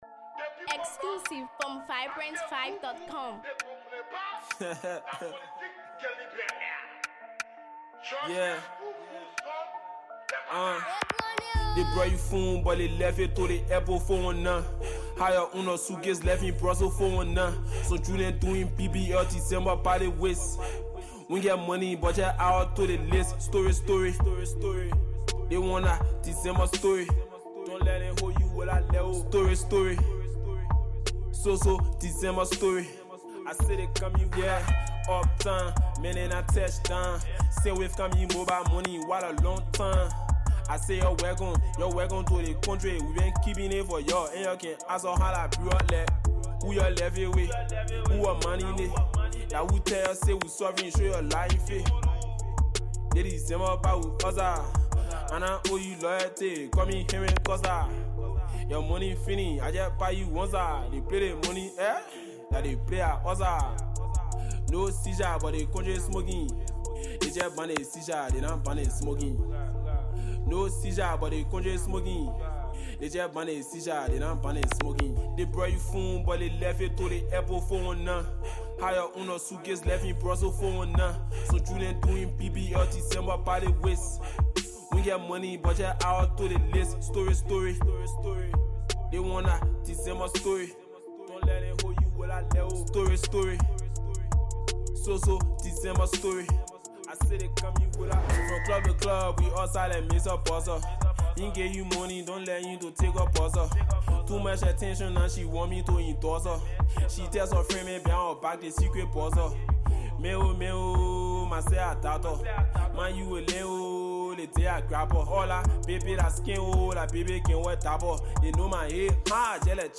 from track 1 to track 4 is a hard tempo!